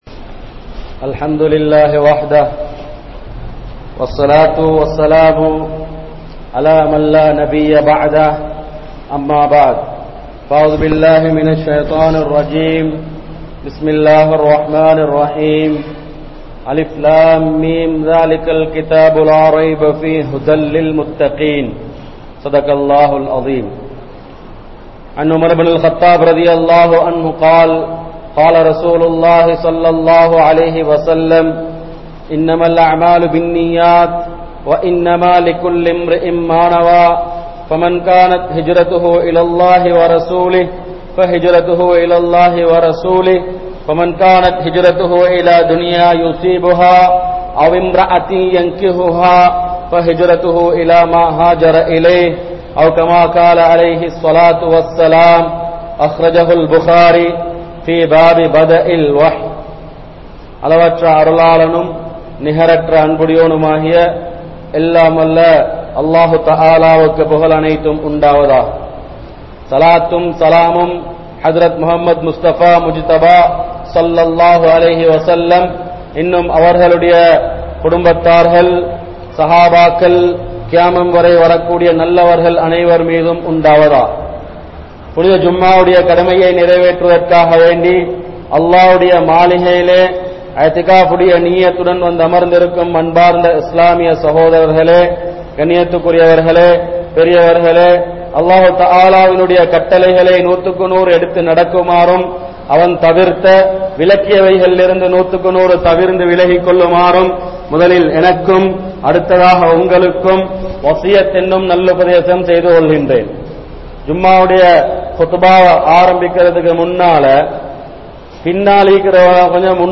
Manitharhal Kirumihalaa?(மனிதர்கள் கிருமிகளா?) | Audio Bayans | All Ceylon Muslim Youth Community | Addalaichenai
Kurunegala, Mallawapitiya Jumua Masjidh